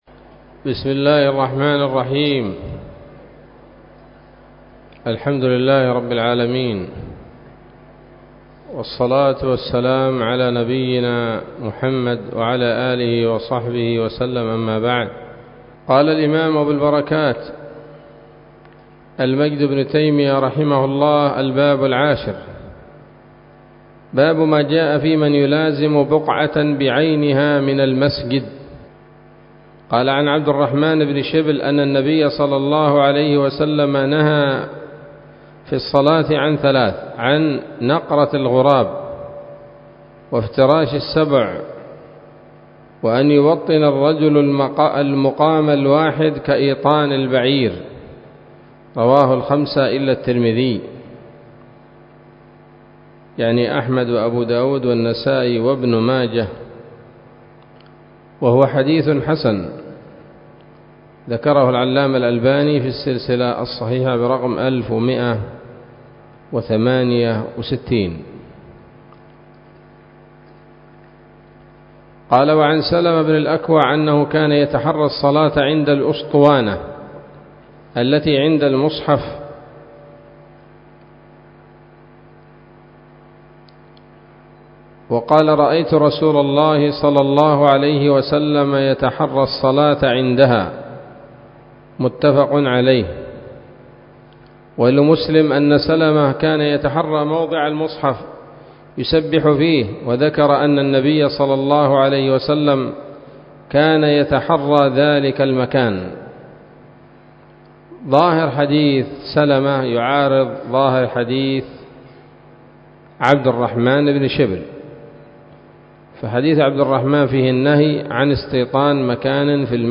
الدرس الثاني عشر من ‌‌‌‌‌‌أَبْوَاب مَوْقِف الْإِمَام وَالْمَأْمُوم وَأَحْكَام الصُّفُوف من نيل الأوطار